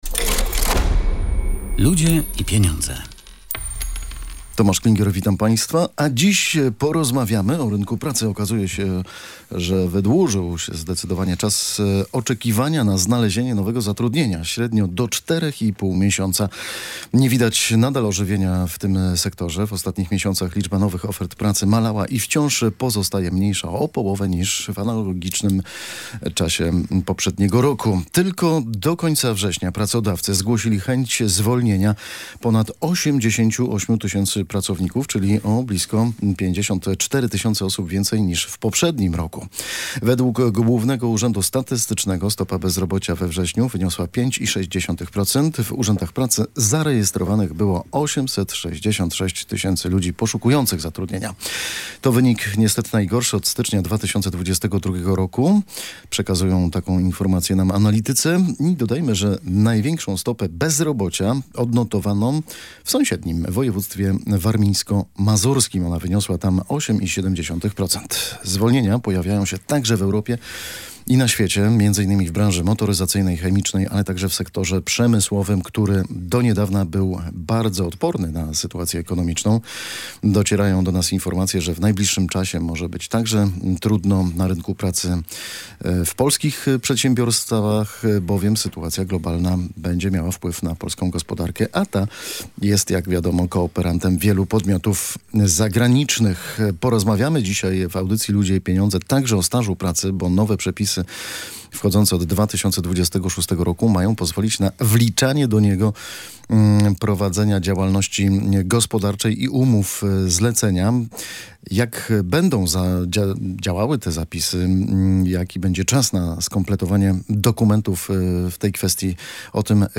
Dyskusja wokół rynku pracy.